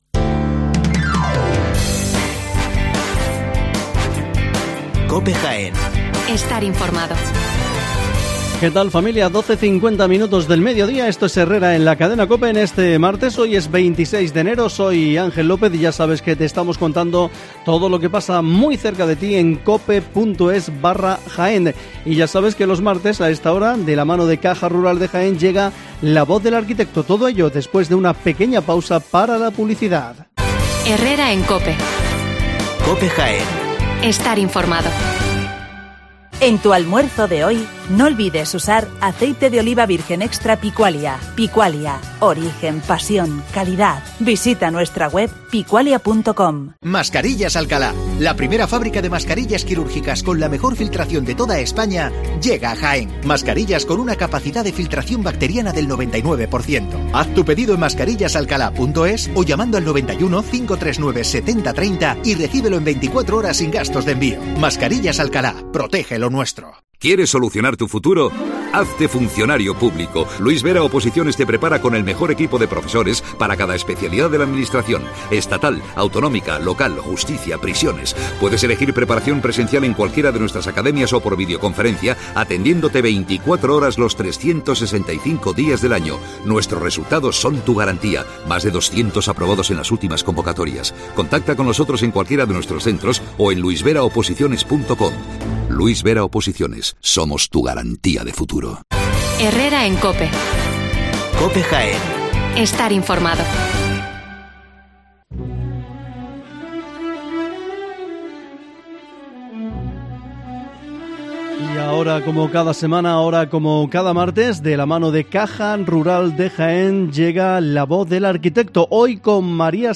Espacio semanal denominado “La Voz del Arquitecto”, bajo el Convenio de Colaboración con COPE cuyo emisión tiene lugar los martes en la citada cadena, con el patrocinio de Caja Rural de Jaén.